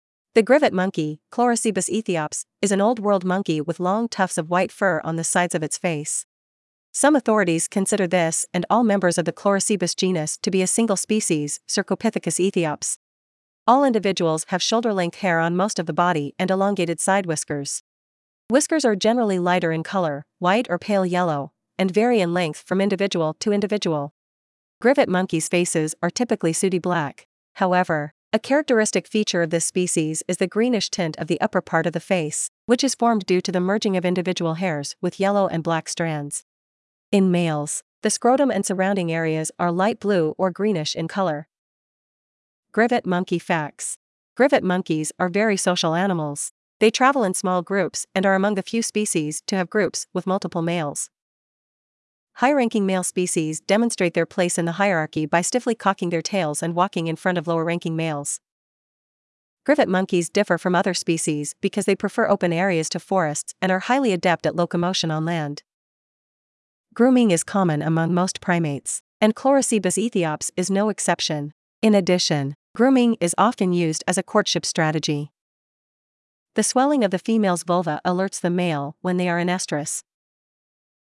Grivet Monkey
Grivet-Monkey.mp3